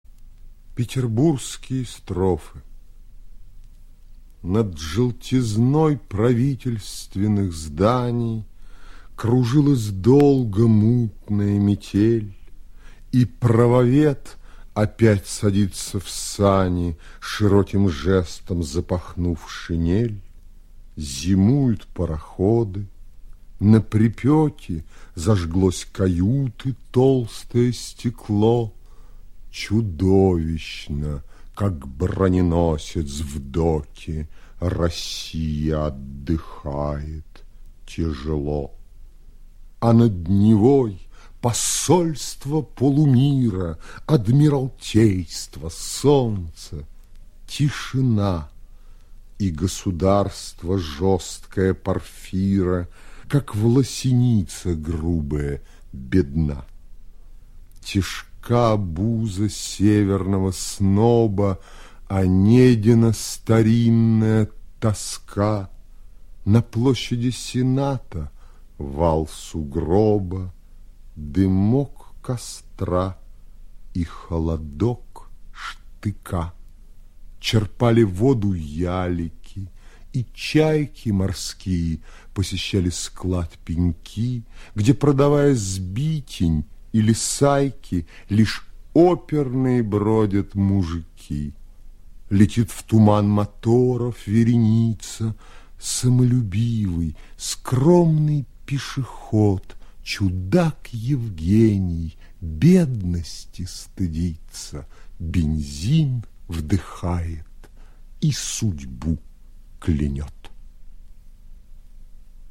1. «Осип Мандельштам читает Михаил Козаков – Петербургские строфы (1913)» /